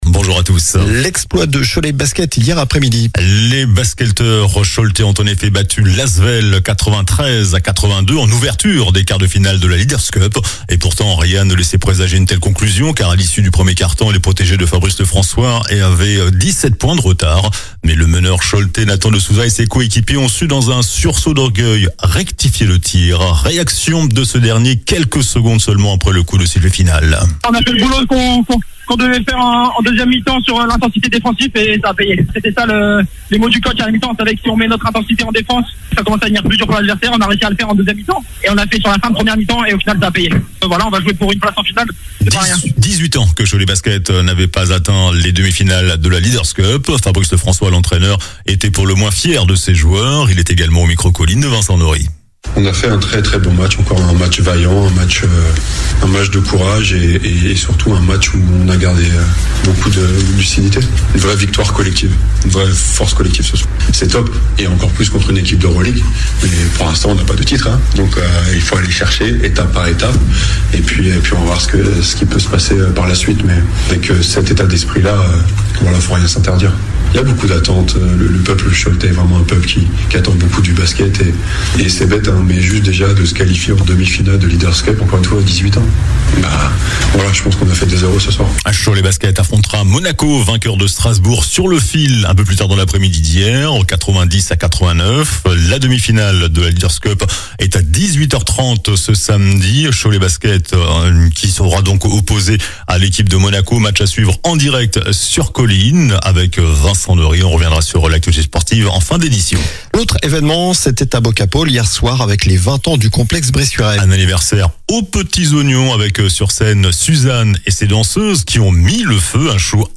Journal du samedi 21 février